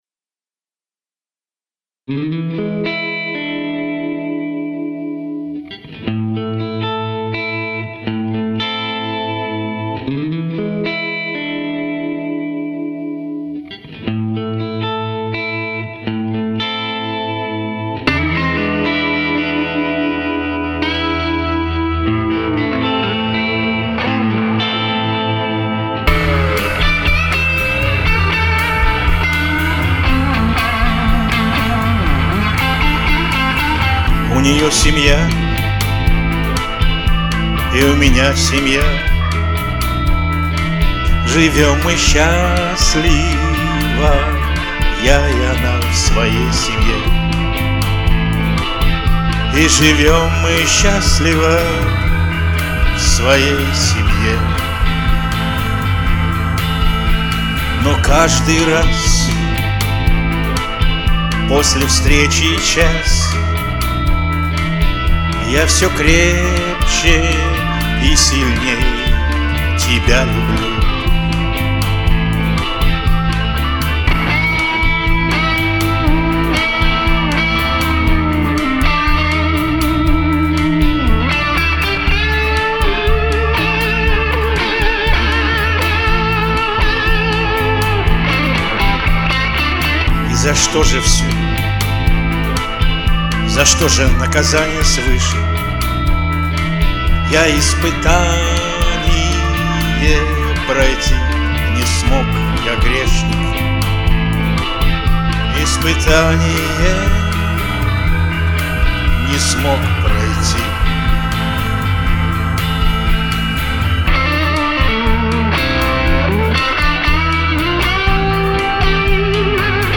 Поем блюз навеселе))) Комментарий соперника: